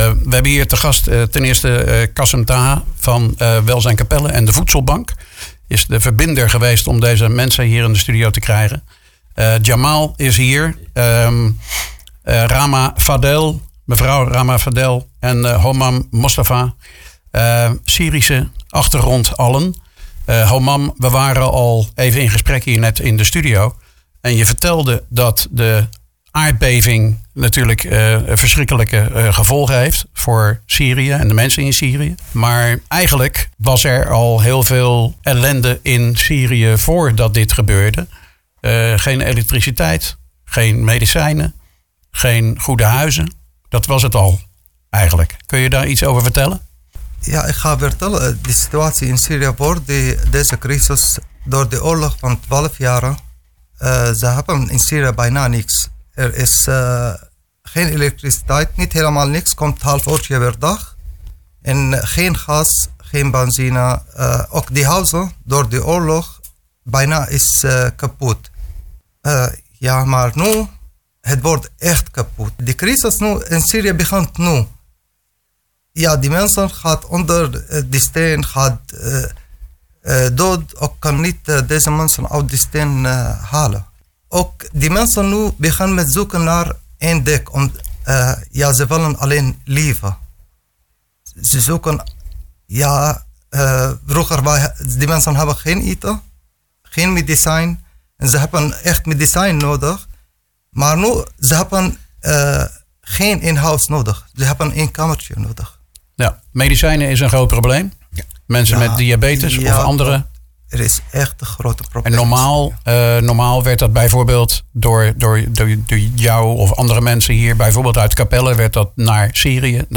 De verschrikkelijke aardbevingsramp in Turkije en Syri� raakt ook Capelle. In Capelle wonen mensen met naaste familie In Syri� die geen dak boven hun hoofd hebben, medicijnen nodig hebben en geen toekomst meer zien. Drie van hen deden hun emotionele verhaal in IJssel-nieuws.